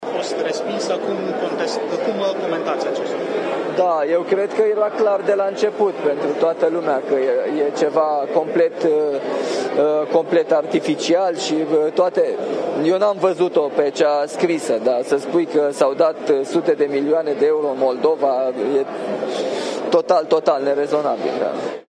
Acesta a fost anunțat de jurnaliștii prezenți la un forum dedicat securității în zona Mării Negre.